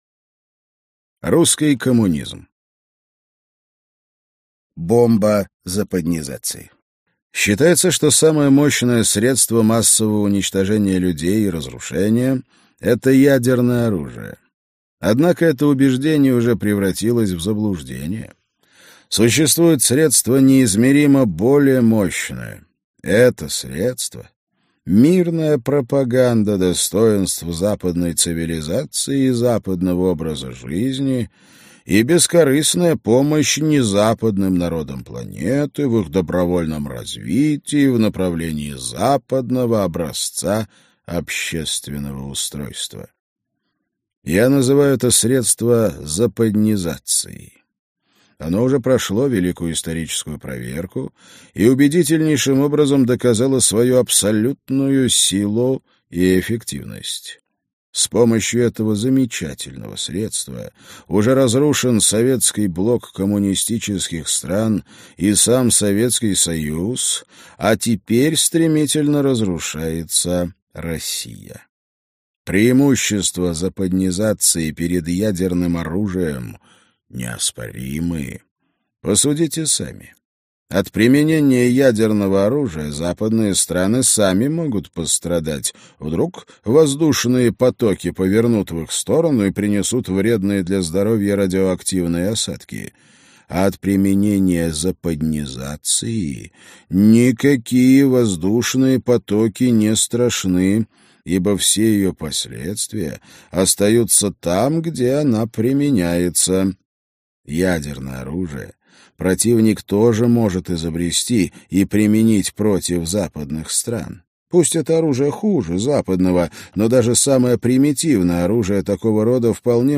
Аудиокнига Русская трагедия. Часть 2 | Библиотека аудиокниг